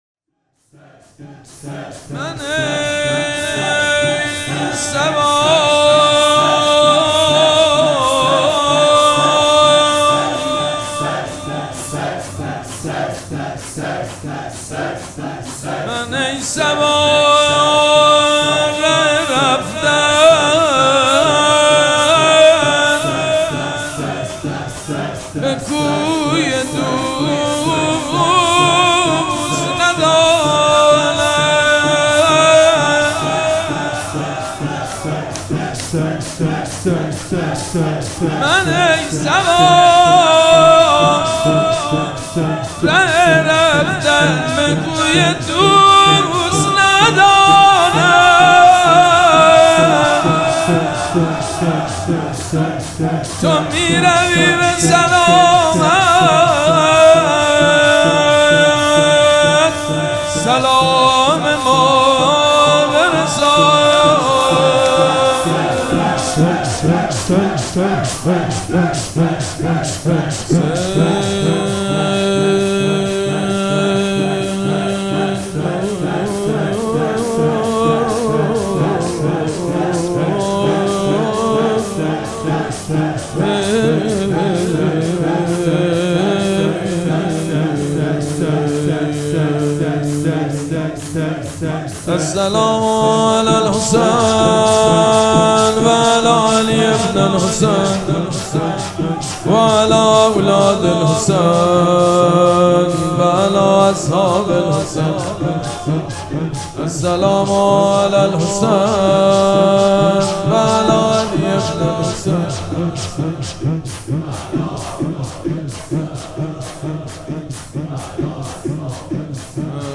نغمه خوانی